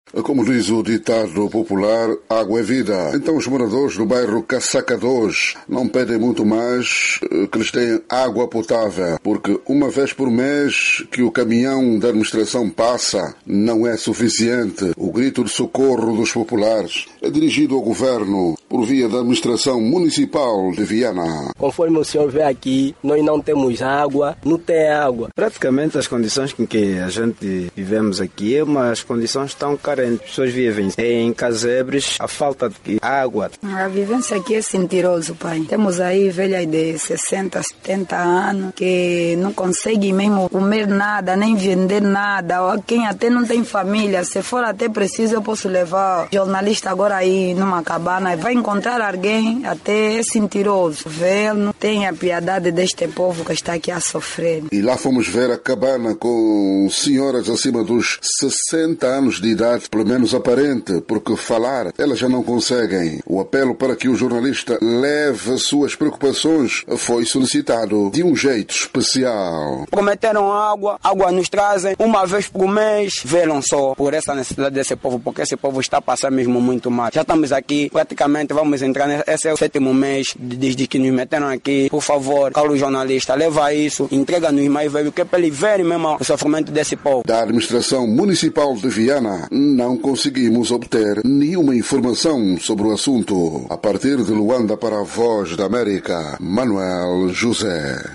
“Conforme o senhor vê aqui não à água”, disse um morador à VOA, enquanto outro prefere mostrar “a extrema carência das pessoas que vivem em casebres, mas o que mais precisam é de água''.